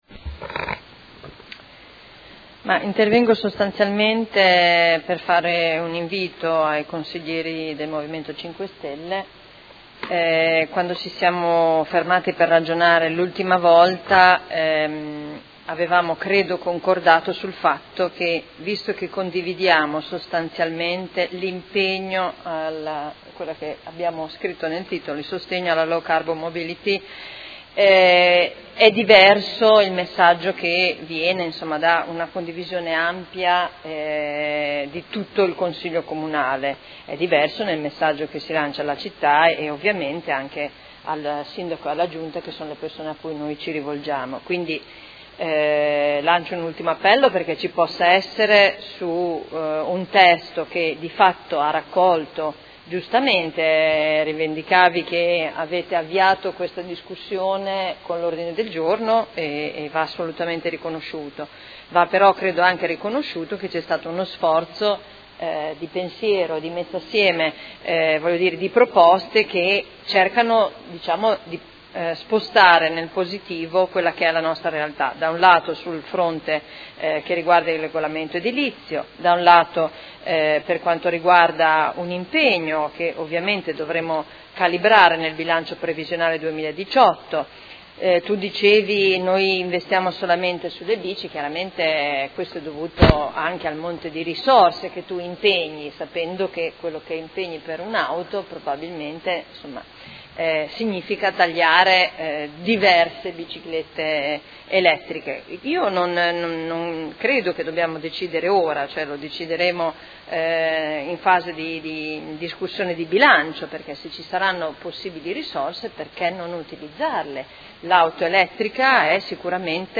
Simona Arletti — Sito Audio Consiglio Comunale
Seduta del 30/11/2017.